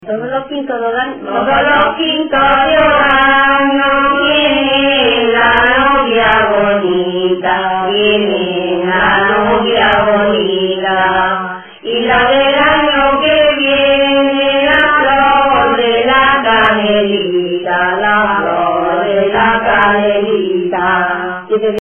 Materia / geográfico / evento: Canciones populares Icono con lupa
Arenas del Rey (Granada) Icono con lupa
Secciones - Biblioteca de Voces - Cultura oral